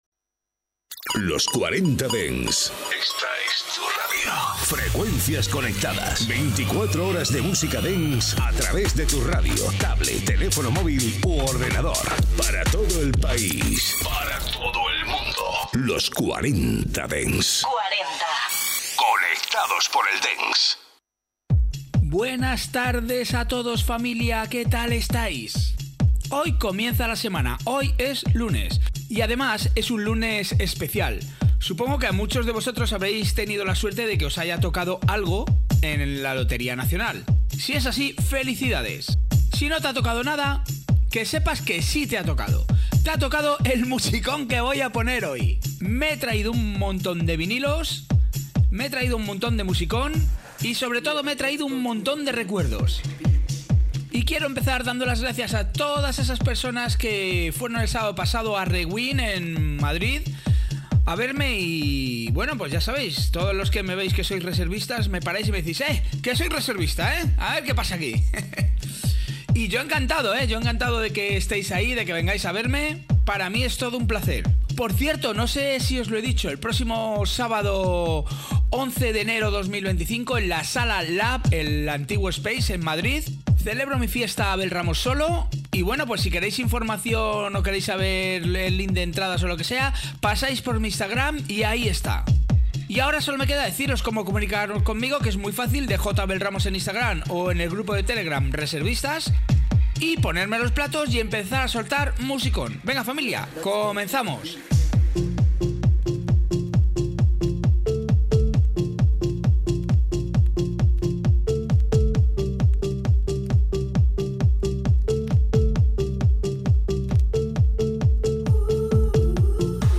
Escucha todos los temazos clásicos y míticos de la música dance de las últimas décadas